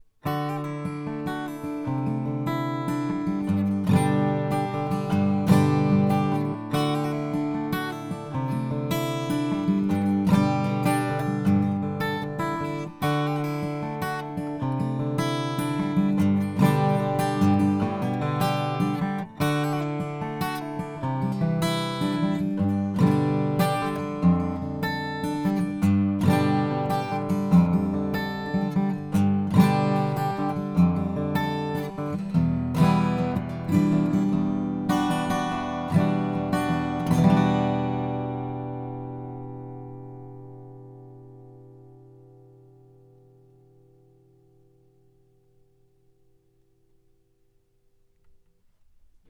Gitarre – Original ohne Kompression
chandler_limited_rs124_test__gitarre_dry.mp3